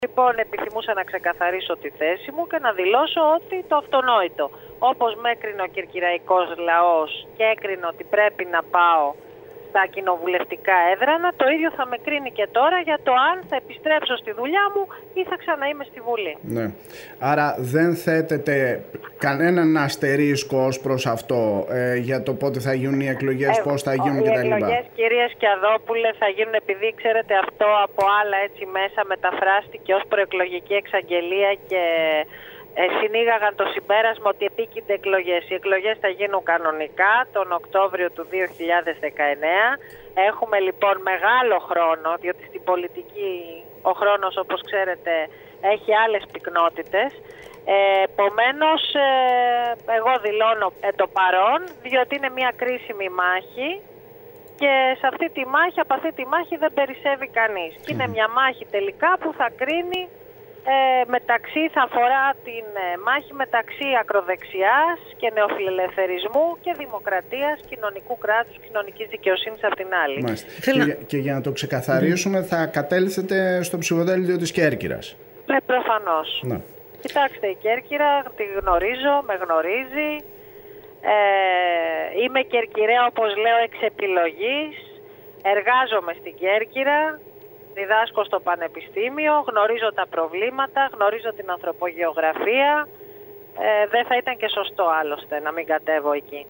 Μιλώντας στην ΕΡΤ Κέρκυρας, η κυρία Βάκη τόνισε ότι ο κερκυραϊκός Λαός που αποφάσισε να την στείλει ως εκπρόσωπό του στην εθνική αντιπροσωπεία είναι αυτός που θα αποφασίσει αν θα συνεχίσει να τον εκπροσωπεί ή θα γυρίσει στα πανεπιστημιακά της καθήκοντα.